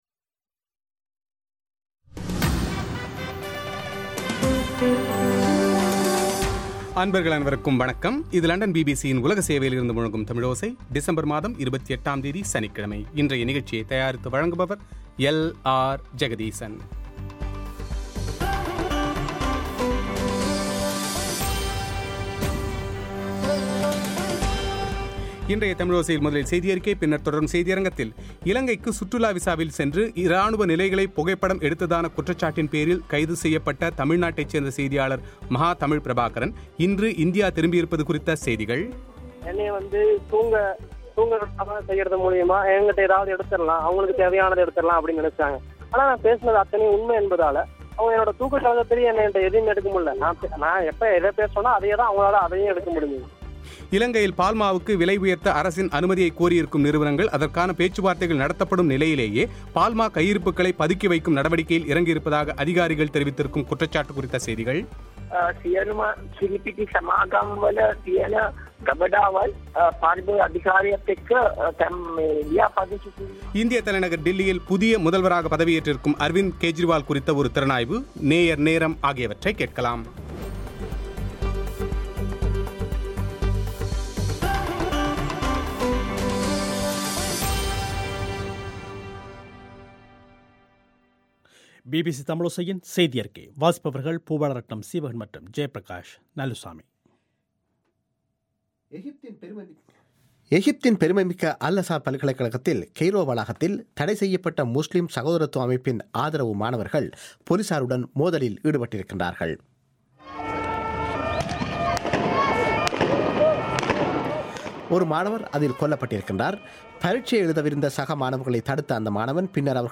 மார்ச் மாதம் ஜெனீவா மனித உரிமைகள் பேரவையில் அமெரிக்காவால் நிறைவேற்றப்படக்கூடிய தீர்மானம் தொடர்பில் இலங்கை அரசாங்கம் நாட்டின் முக்கிய அறிவுஜீவிகளையும் சமூக செயற்பாட்டாளர்களையும் அழைத்துப் பேச்சு நடத்தியுள்ளது குறித்து பிபிசி தமிழோசையிடம் பேசிய இலங்கையின் சமூக நல்லிணக்க அமைச்சர் வாசுதேவ நாணயக்கார, 13ஆவது அரசியல் சட்டத்திருத்தத்தை முழுமையாக நிறைவேற்ற இலங்கை ஜனாதிபதி மஹிந்த ராஜபக்ஷ தயாராக இல்லை என்று தெரிவித்திருப்பது குறித்து அவரது செவ்வி;